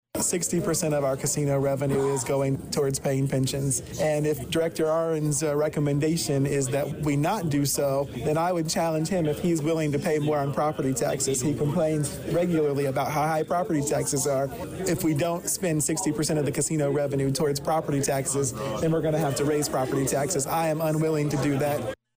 Danville’s Mayor Rickey Williams, Jr; reads that financial map differently.